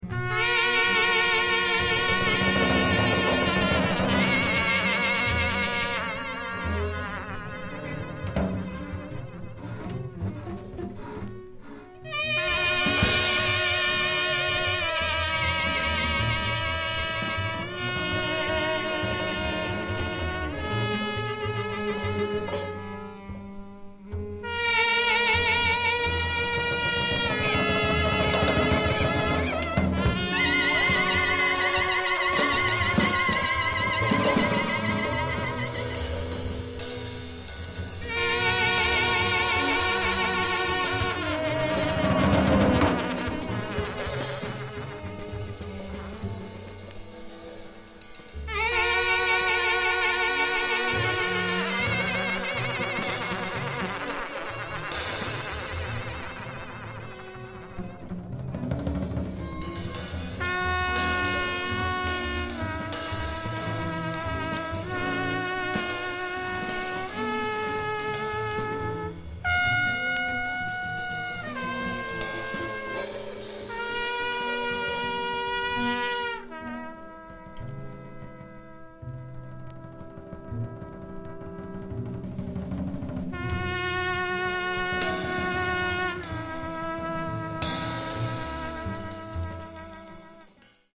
Avant-garde mayhem from the Dondada of “Free Jazz”.